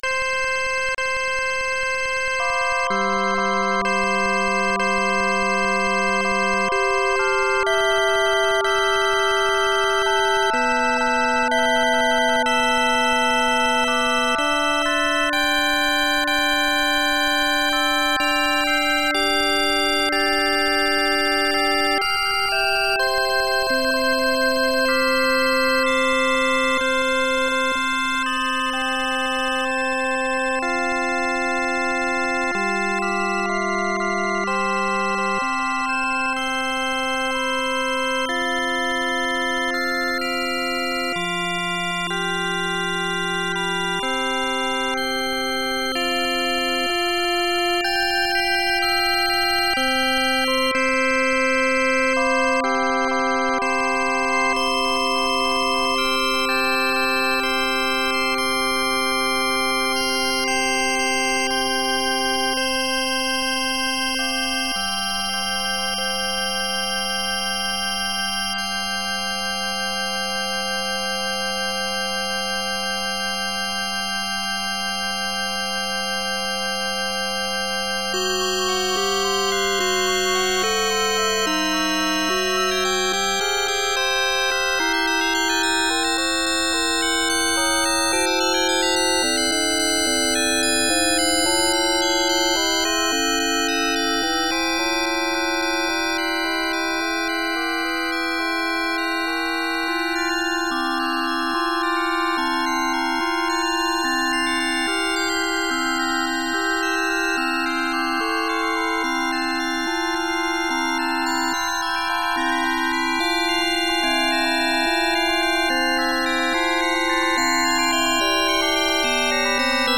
Σερενάτα για έγχορδα
Σερενάτα για έγχορδα.mp3